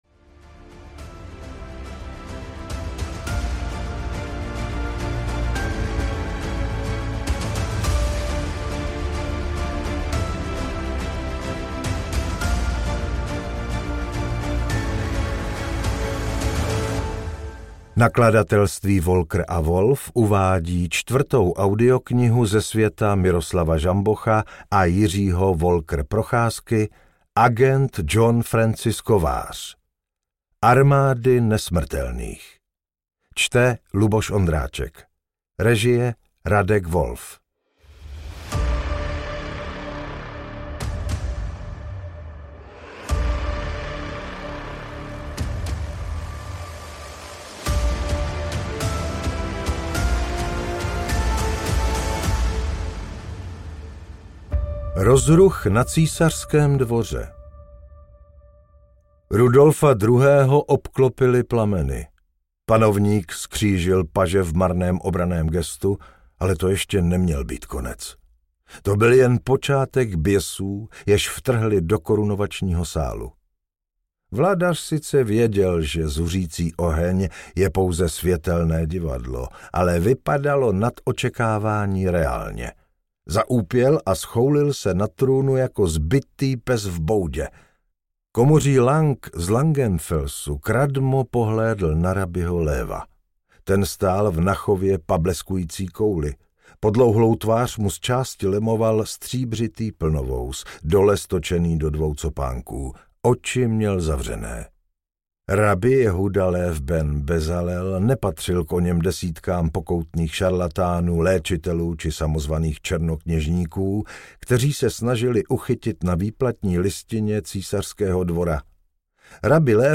Armády nesmrtelných audiokniha
Ukázka z knihy